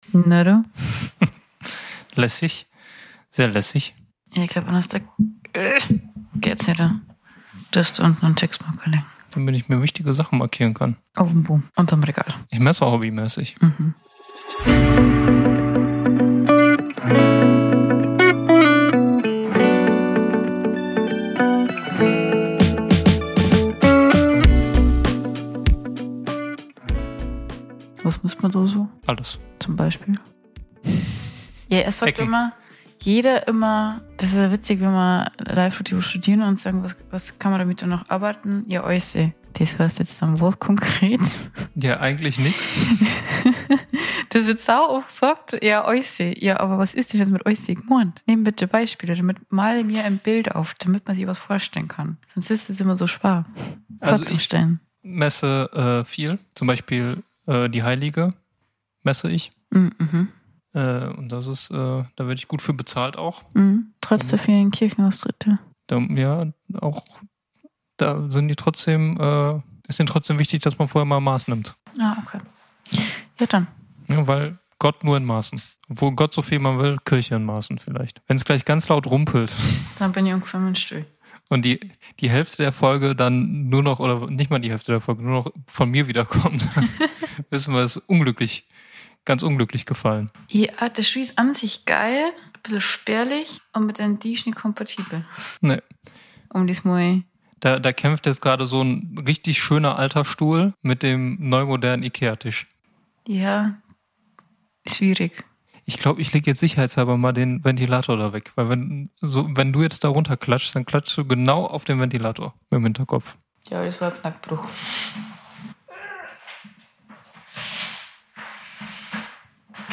Es wird auf den Tisch gehauen, auch geklopft, Phrasen gedroschen, und alles andere, was unseren Podcast ausmacht, glaub ich...